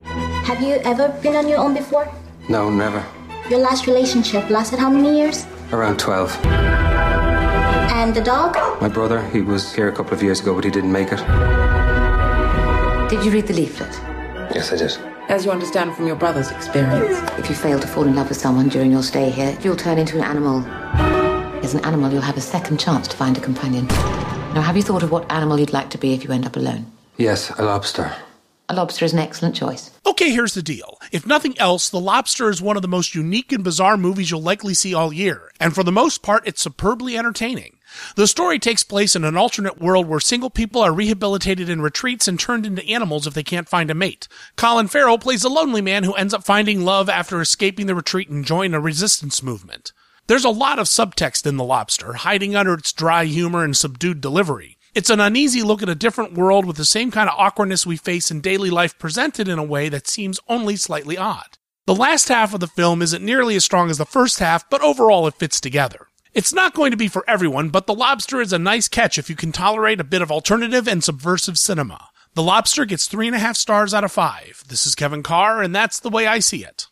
‘The Lobster’ Radio Review